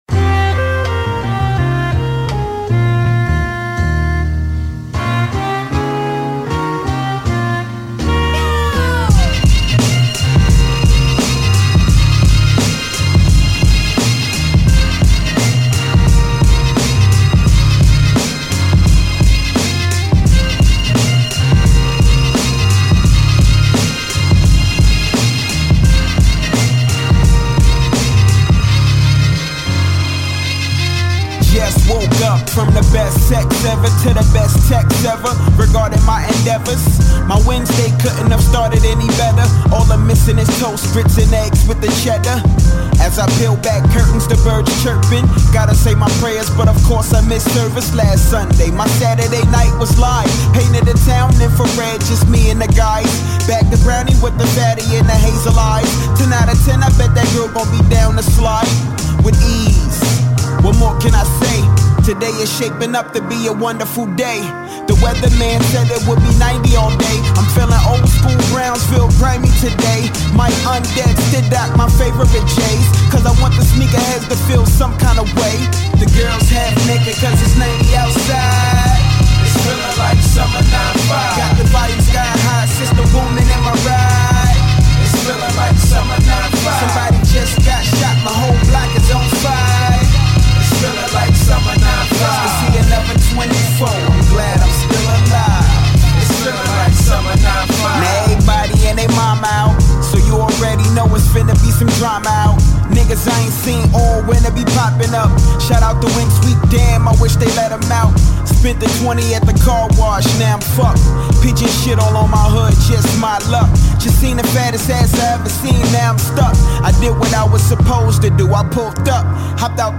Real Hip Hop!